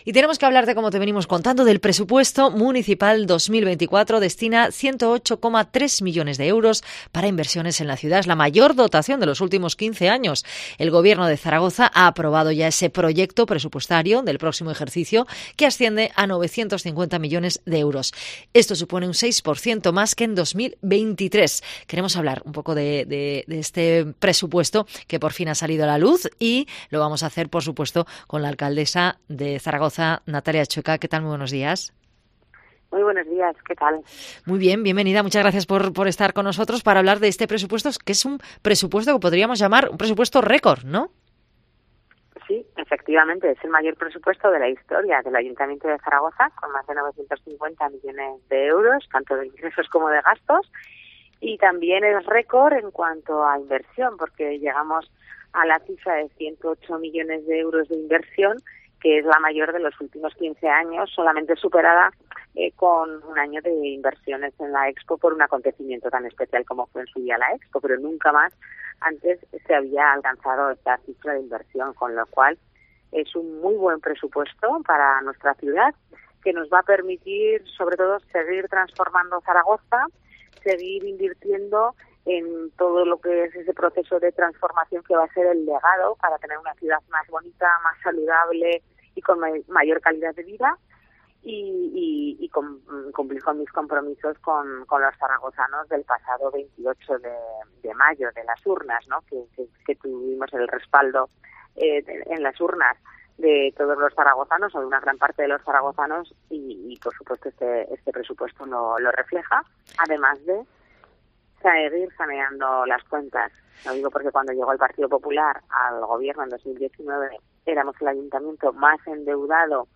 Entrevista a la alcaldesa de Zaragoza, Natalia Chueca, sobre los presupuestos municipales de 2024